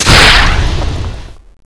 knockgrenade_exp.wav